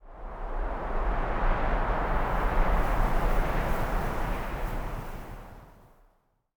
housewind15.ogg